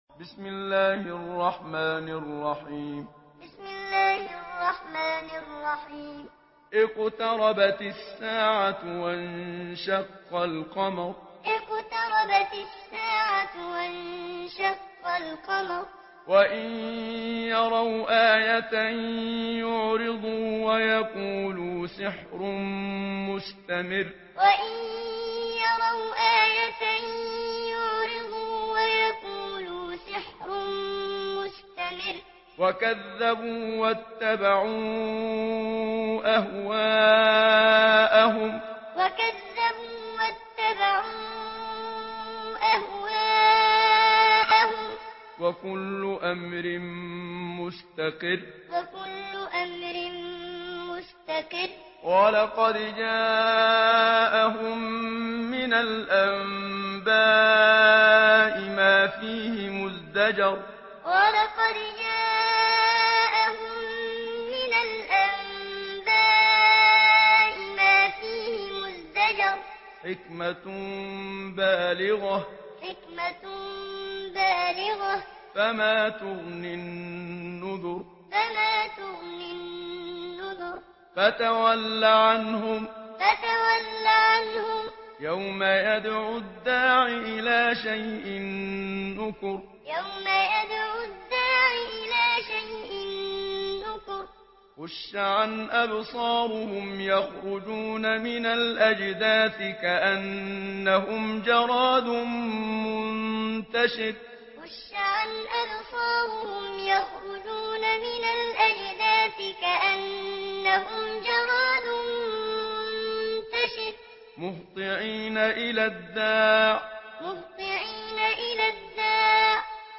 Surah القمر MP3 in the Voice of محمد صديق المنشاوي معلم in حفص Narration
Surah القمر MP3 by محمد صديق المنشاوي معلم in حفص عن عاصم narration.